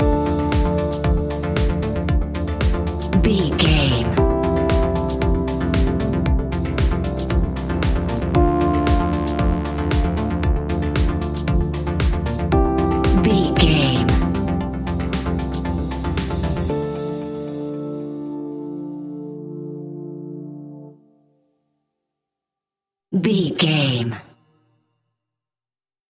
Aeolian/Minor
funky
groovy
uplifting
driving
energetic
drum machine
electric piano
synthesiser
electro house
synth leads
synth bass